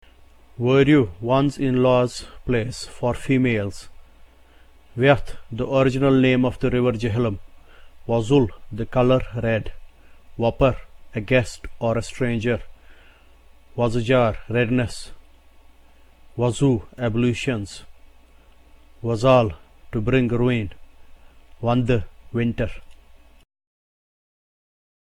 The Consonants
Y is pronounced as the letter Y in the English word YES.
R is the symbol used for the sound represented by the sound of the letter R in the English RED.
The symbol L is a consonant that has the same sound as L in the English LEG.
The symbol V is used to denote a sound that is identical with that of the letter V in the English VAN.